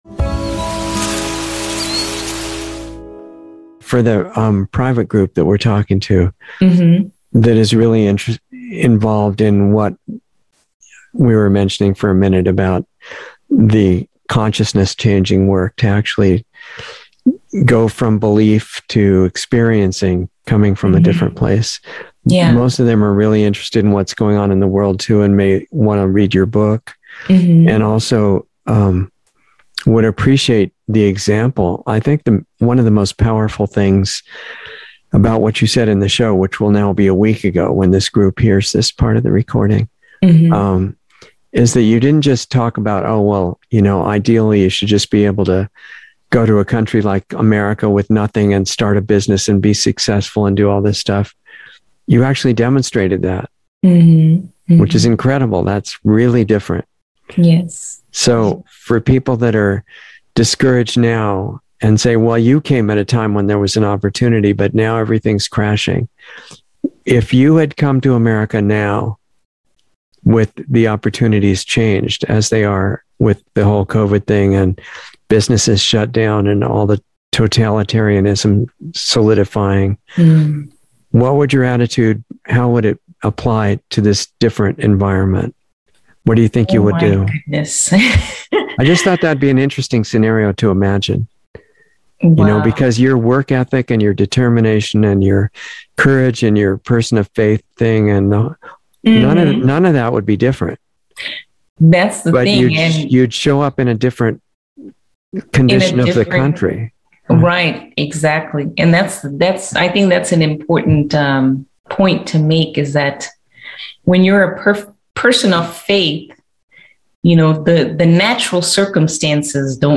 Insider Interview 2/23/22